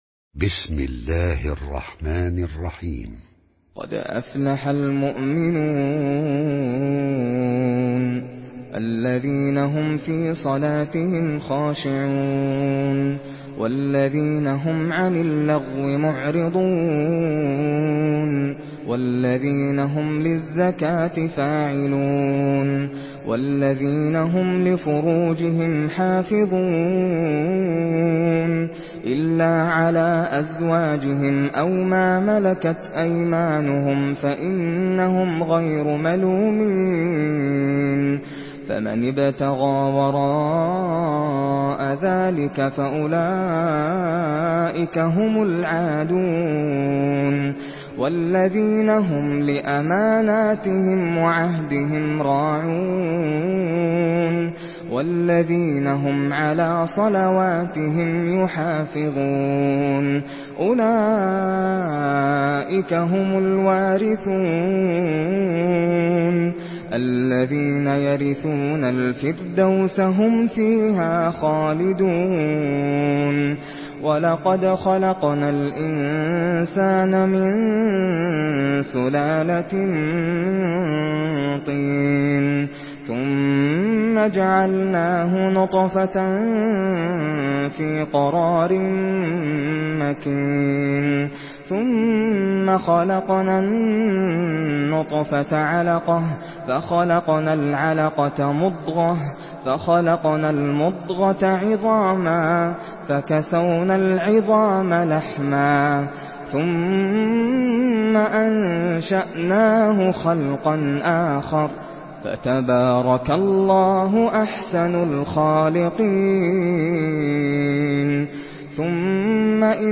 سورة المؤمنون MP3 بصوت ناصر القطامي برواية حفص
مرتل